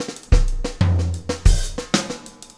Extra Terrestrial Beat 04.wav